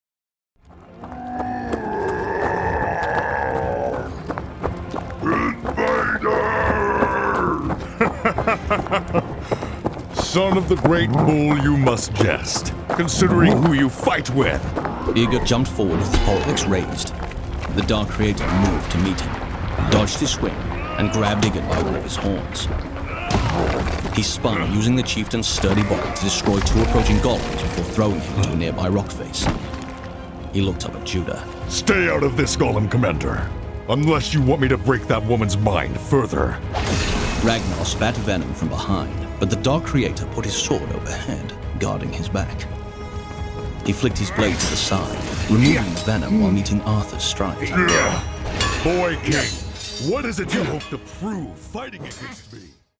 Exclusive Newsletter Audiobook Sample!
I've been collecting sounds to use during my audio designing down time, and well, I'll let you listen for yourself. Use headphones if you're able to!